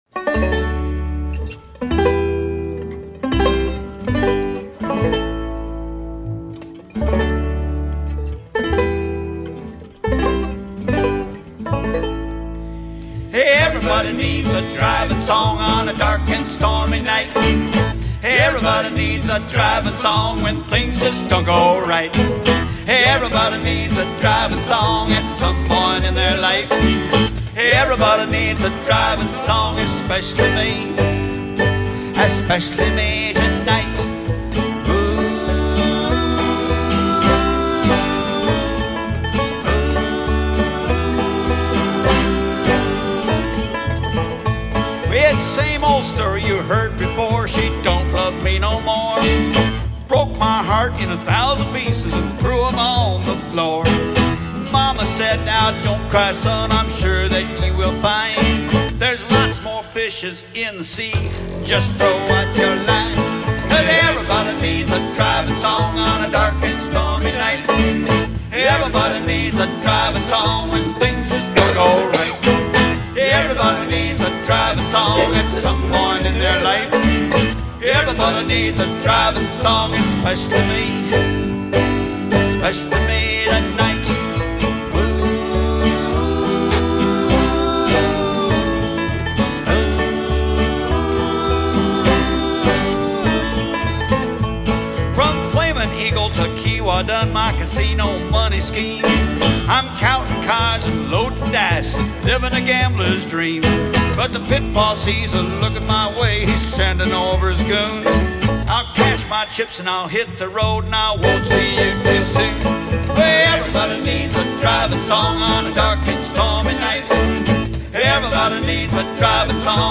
a collection of original vocal songs
Recorded live on the road during summers 2010 and 2011.